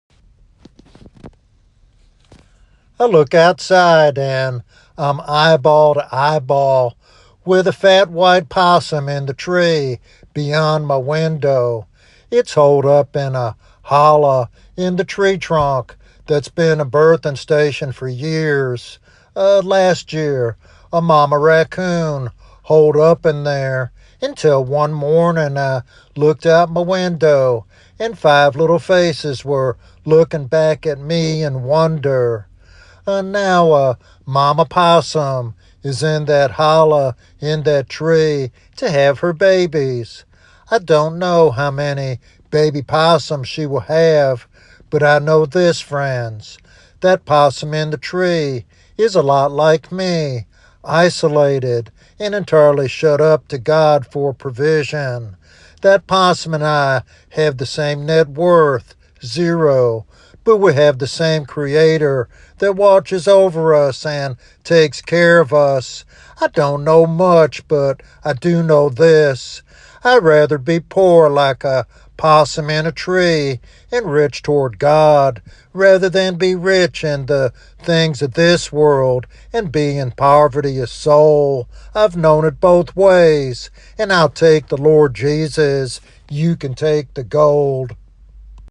This devotional sermon encourages believers to embrace spiritual richness even in times of worldly lack.